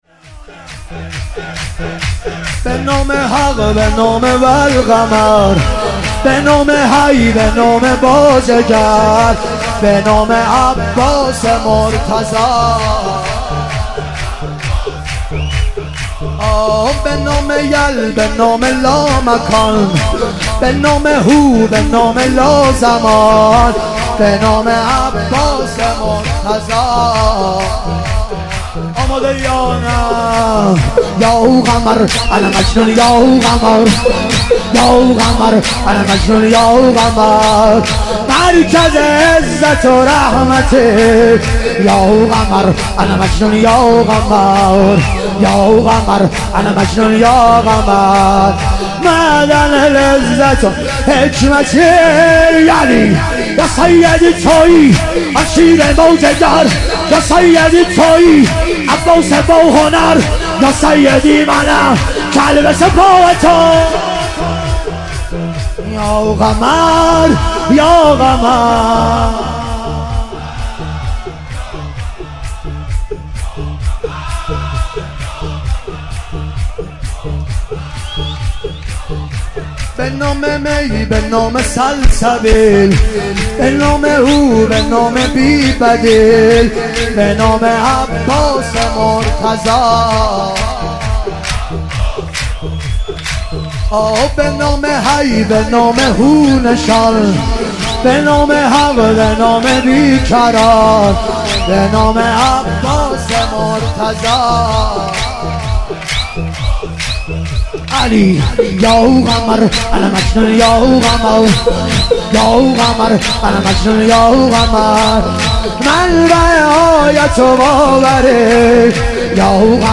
ظهور وجود مقدس حضرت علی اکبر علیه السلام - شور